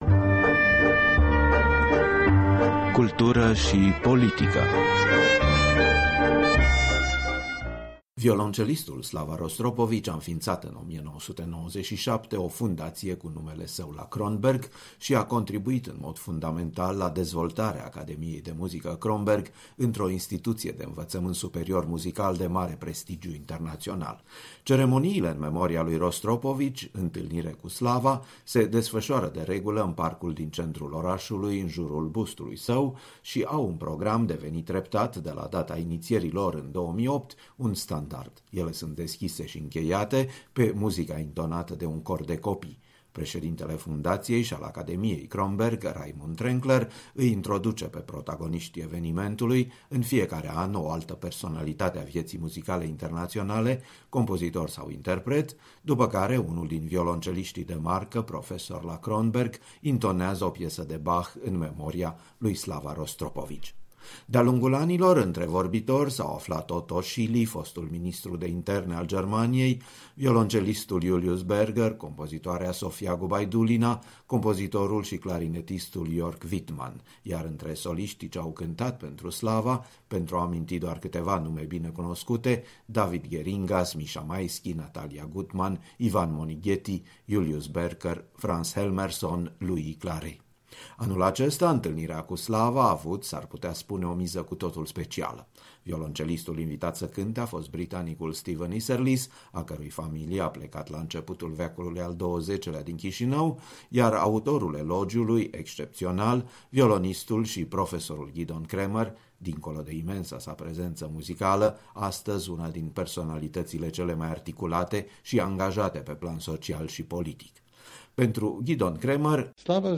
Gidon Kremer face elogiul violoncelistului Slava Rostropovici la Kronberg Academy
Reportaj de la o „Întîlnire cu Slava” Rostropovici și un elogiu adus violoncelistului de Gidon Kremer.